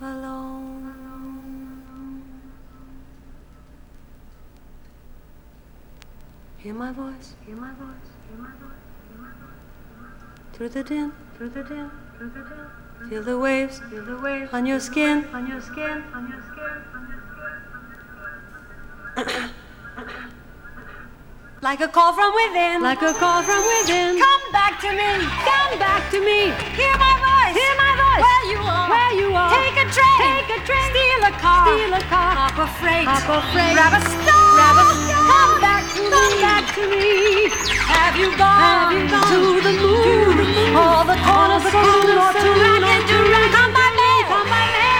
民族音楽や世界の楽器、最新の電子楽器も、貪欲にぶち込んでミックス。
Pop, Stage&Screen, Experimemtal　USA　12inchレコード　33rpm　Stereo